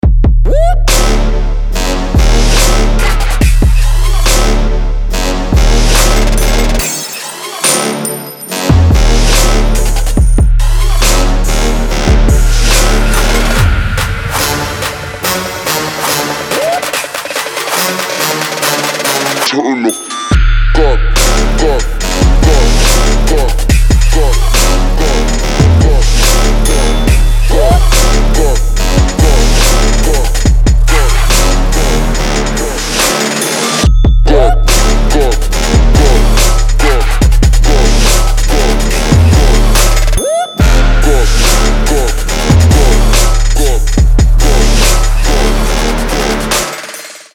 • Качество: 320, Stereo
Trap
Bass
Мощь и грубость Трап музыки!